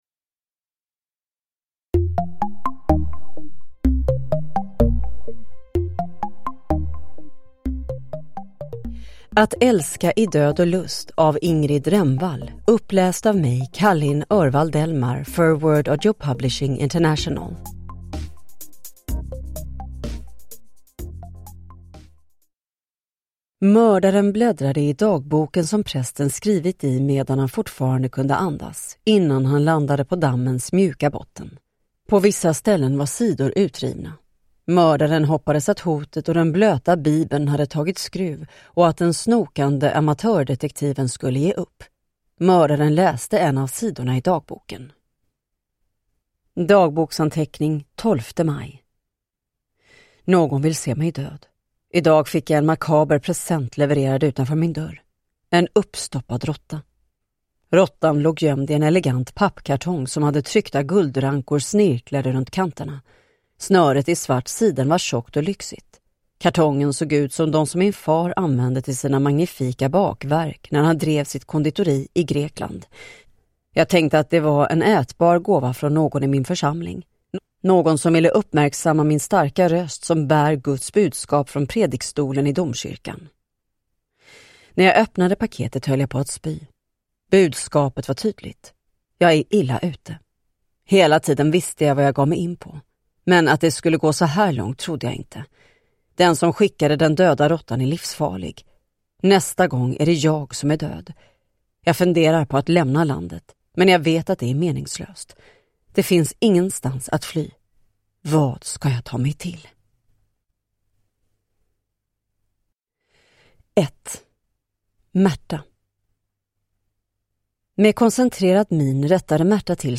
Att älska i död och lust – Ljudbok
Deckare & spänning Deckare & spänning - Ljudböcker Njut av en bra bok Visa alla ljudböcker